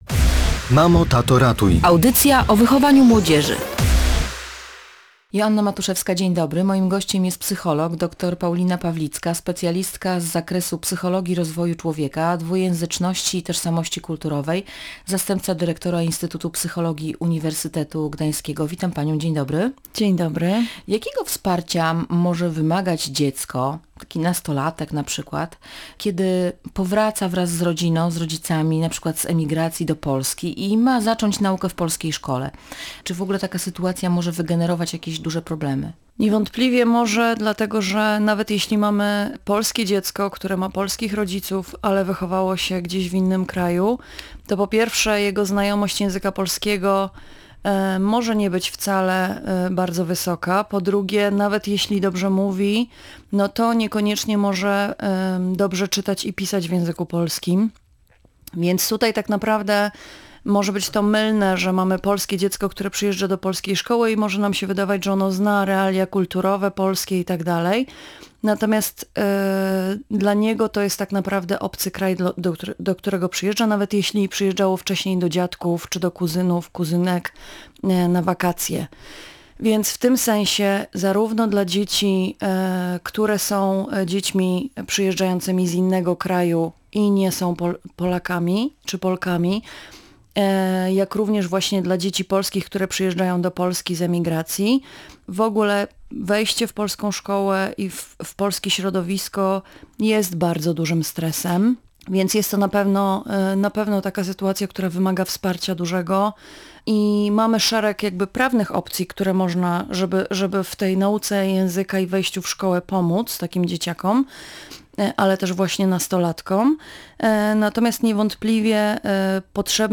Nastolatek, który wychował się zagranicą i przyjeżdża do Polski, musi pokonać wiele barier i problemów - mówiła w Radiu Gdańsk